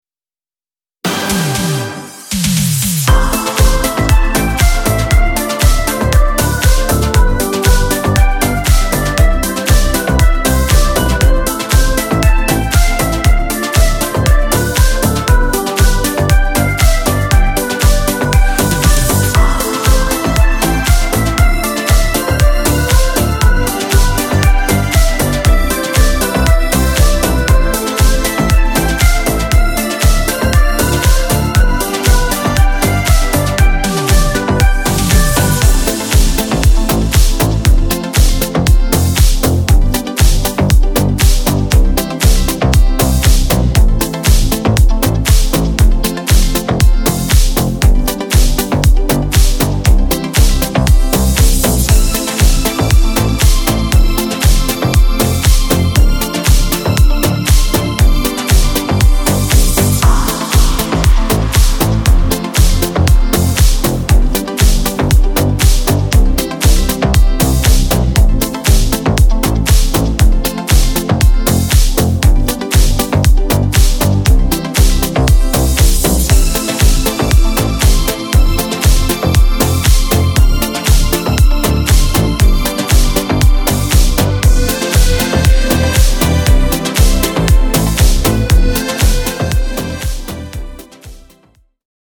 instrumental
Dance